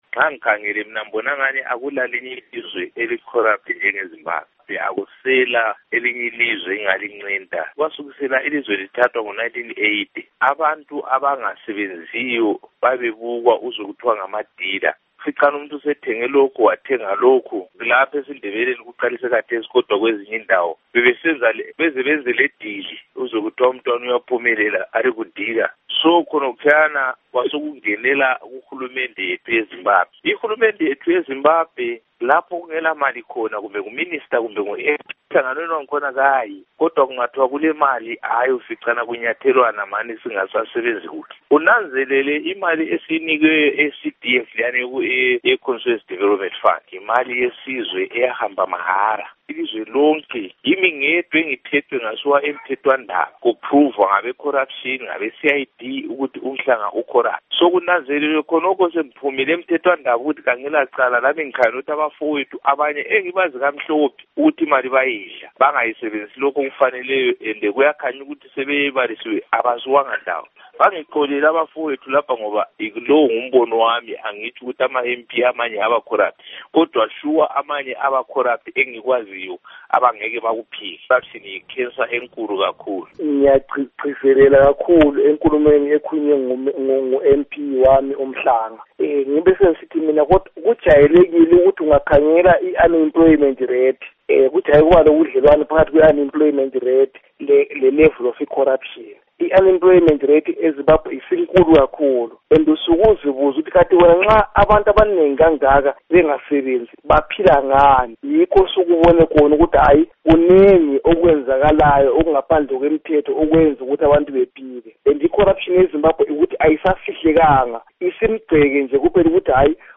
Ingxoxo LoMnu.